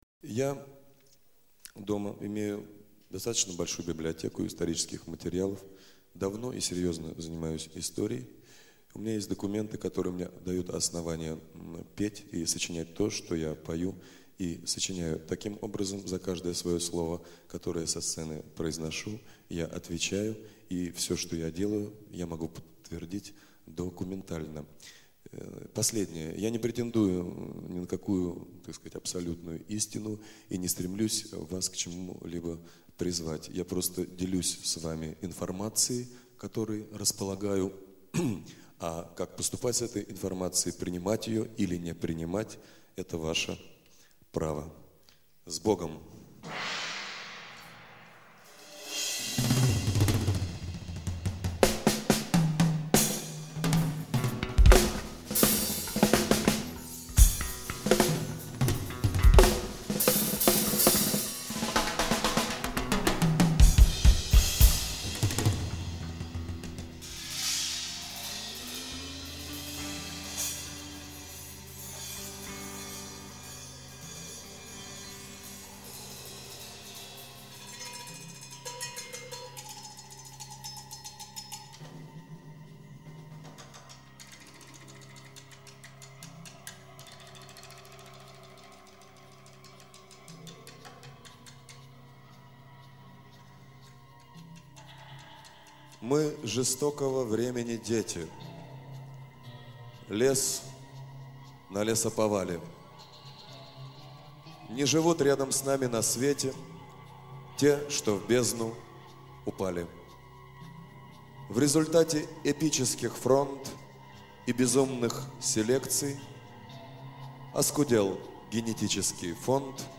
1. «Игорь Тальков – Декламация – “Правда о России” (Live, 1991)» /
Talkov-Deklamaciya-Pravda-o-Rossii-Live-1991-stih-club-ru.mp3